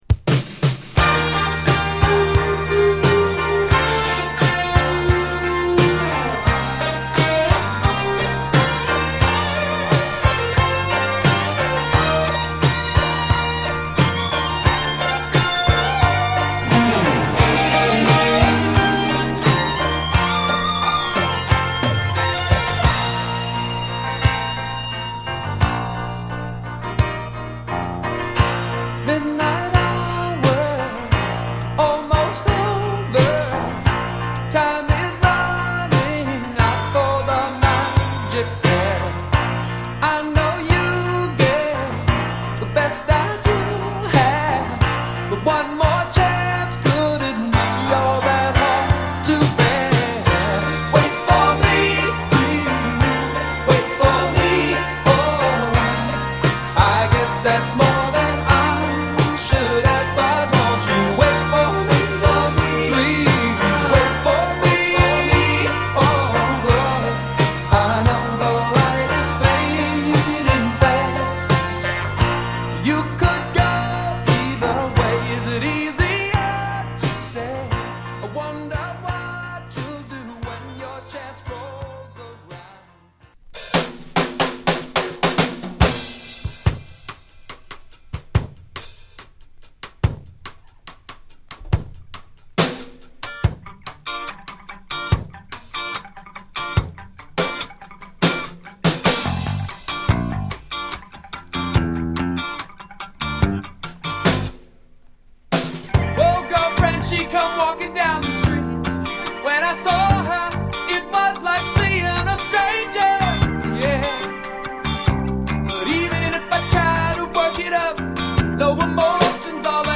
全体的にロック/ポップスな1枚ですが、イントロが「Lil Wayne